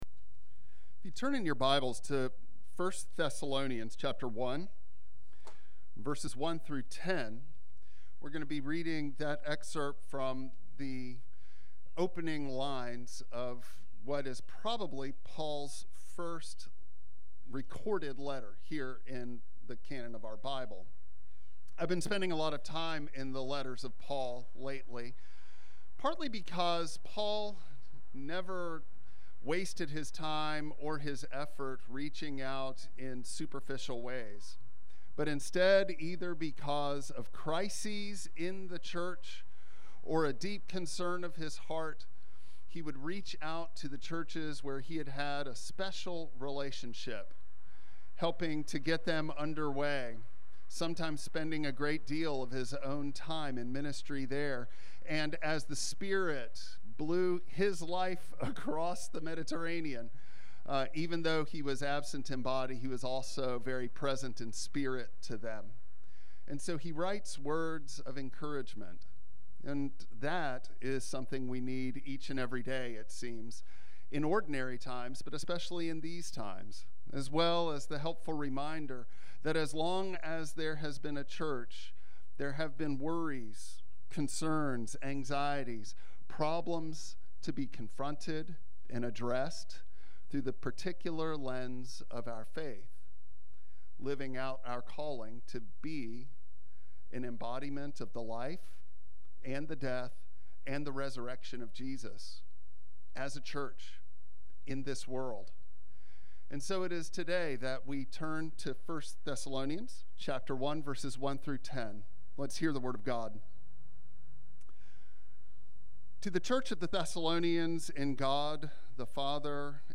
Service Type: Traditional Service